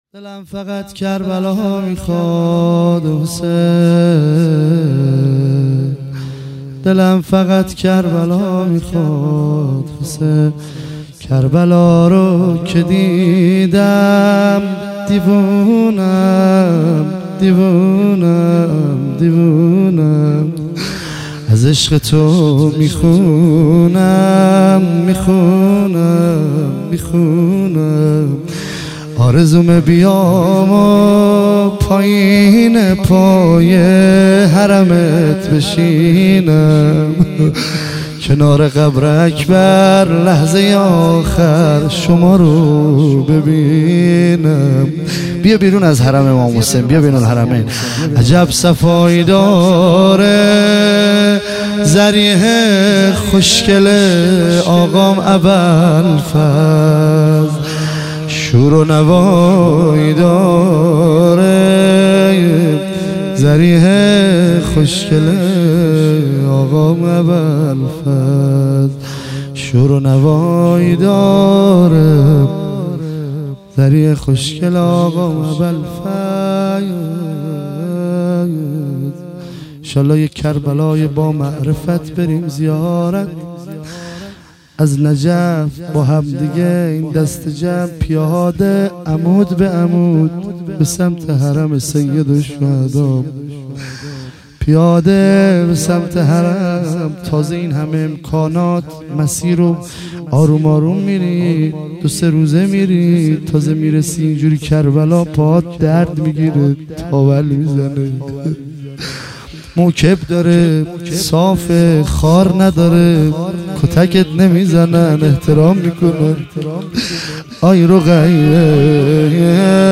مراسم شب هشتم محرم ۱۳۹۷
روضه پایانی